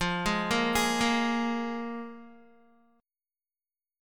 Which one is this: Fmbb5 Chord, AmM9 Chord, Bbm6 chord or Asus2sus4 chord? Fmbb5 Chord